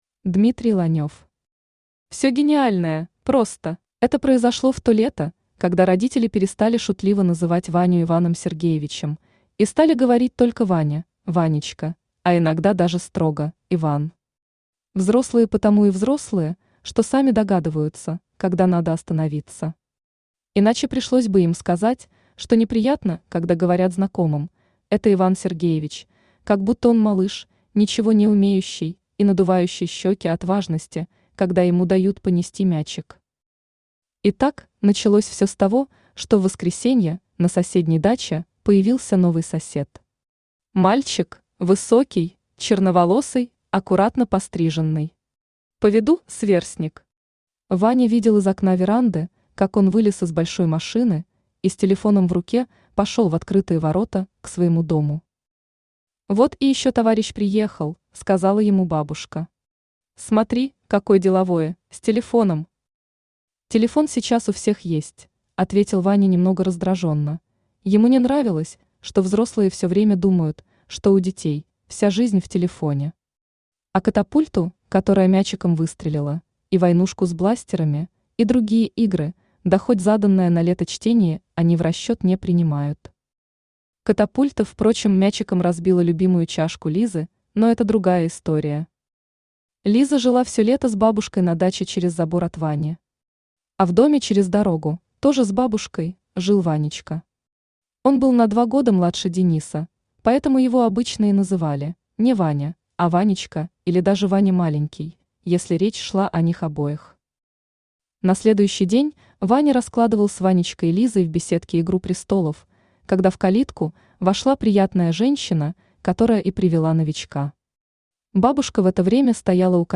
Аудиокнига Все гениальное – просто | Библиотека аудиокниг